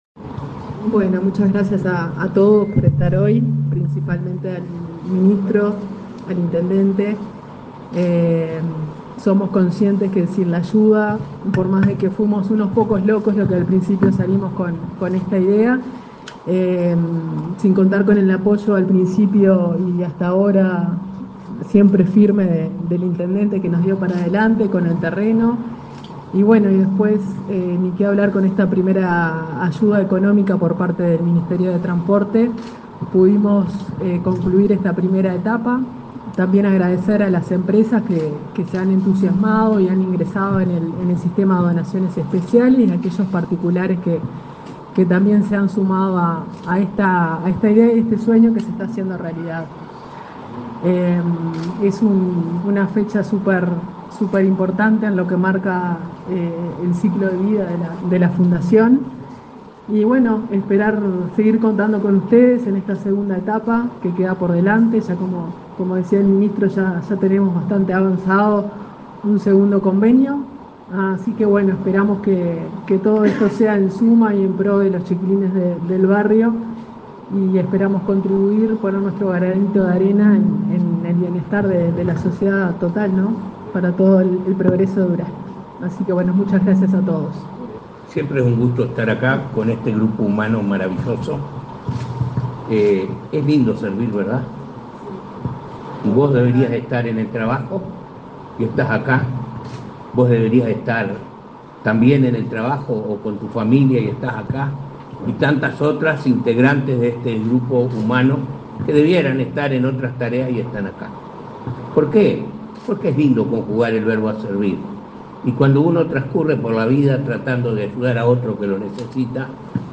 Acto de inauguración de obras por convenio social
Acto de inauguración de obras por convenio social 11/09/2024 Compartir Facebook X Copiar enlace WhatsApp LinkedIn El ministro de Transporte y Obras Públicas, José Luis Falero, participó, este 11 de setiembre, en la inauguración de obras por convenio social con la fundación Impacto, de Las Higueras, en el departamento de Durazno.